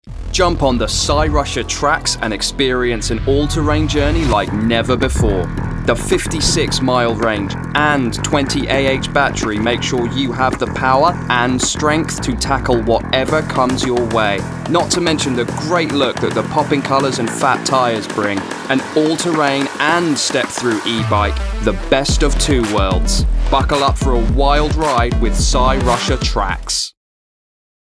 • Male
E Bike Commercial. Engaging, Enthusiastic
E-Bike-commercial.mp3